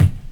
drum2.mp3